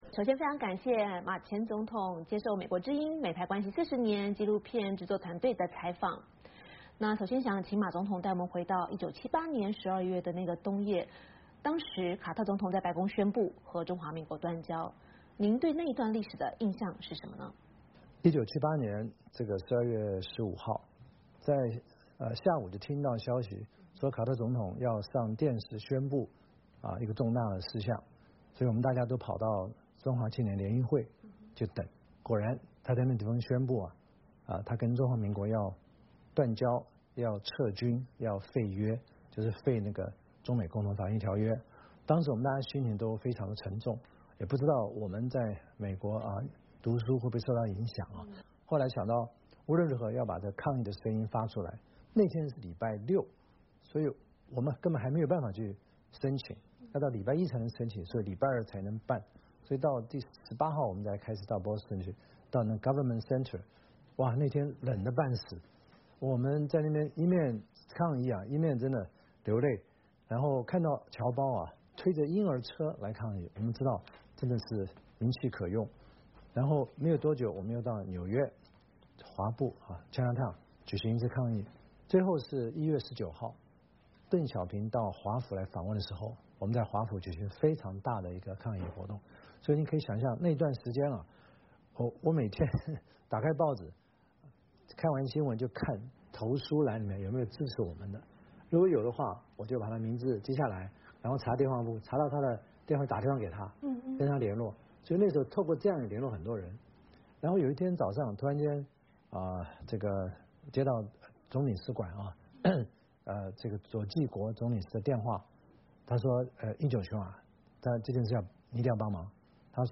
美国之音专访